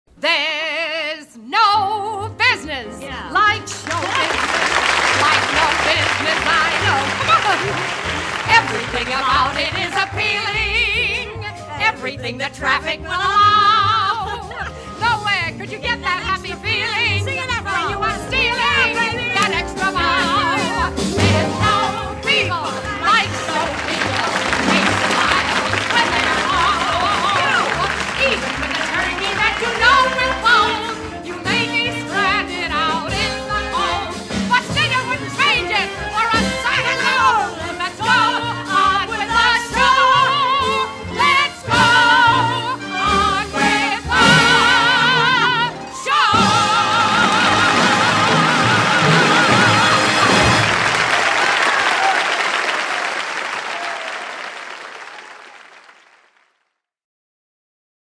Musical   Composer